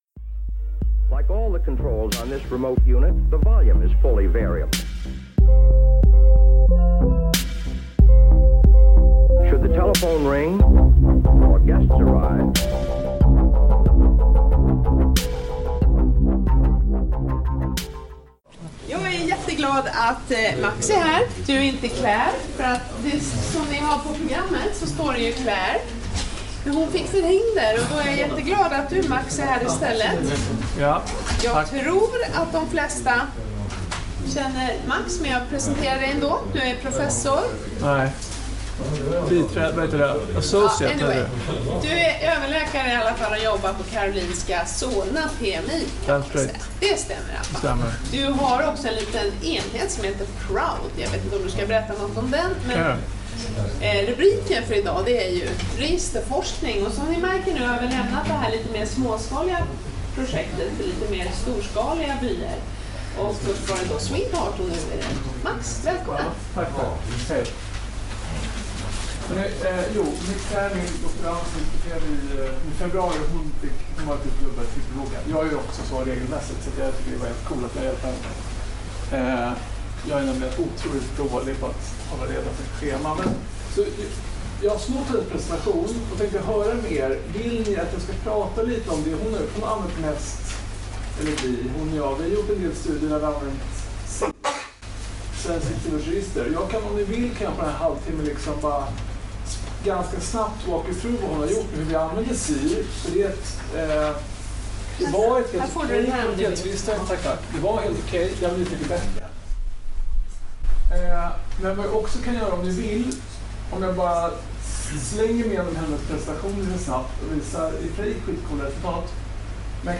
Föreläsningen hölls på ST-fredagen om kvalitetsregister den 7 september 2018 i Huddinge.